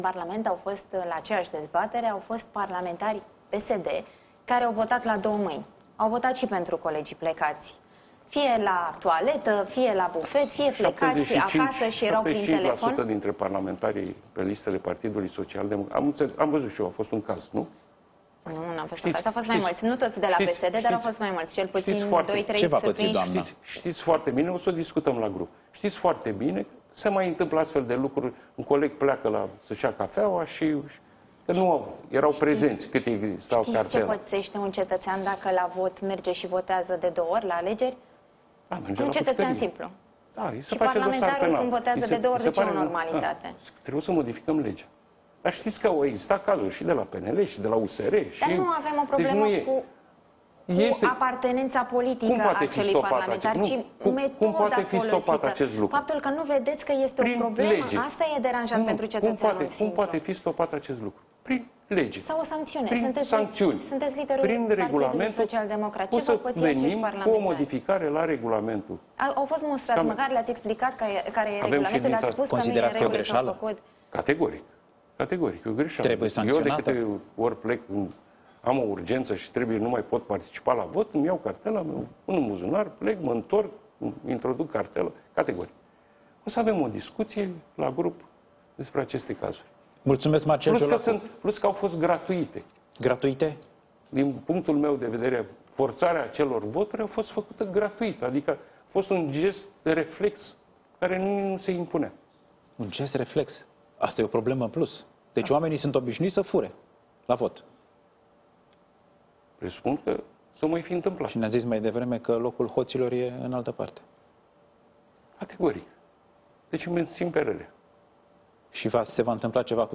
O să venim cu o modificare la Regulamentul camerelor”, a spus liderul social-democrat la Digi24, miercuri seara, întrebat fiind ce se va întâmpla cu parlamentarii PSD care au votat şi în locul colegilor care nu erau prezenţi în sală la şedinţa.